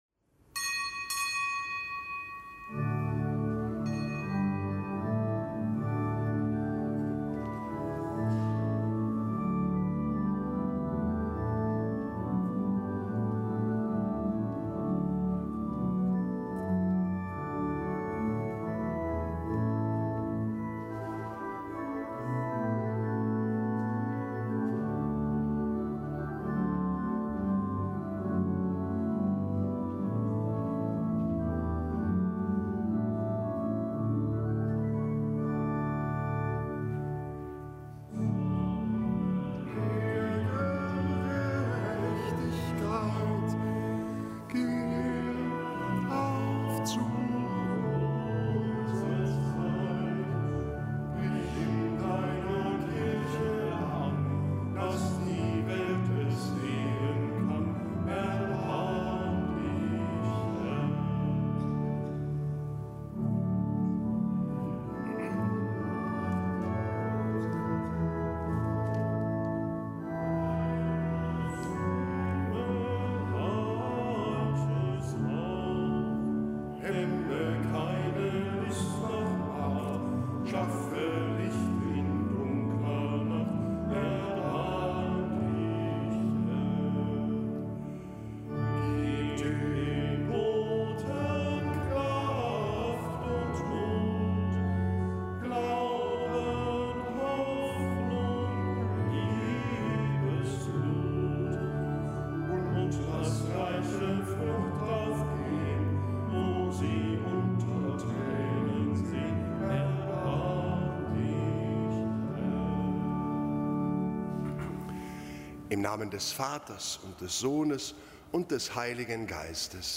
Kapitelsmesse am Gedenktag des Heiligen Timotheus und Heiligen Titus
Kapitelsmesse aus dem Kölner Dom am Gedenktag des Heiligen Timotheus und des Heiligen Titus, Bischöfe und Apostelschüler.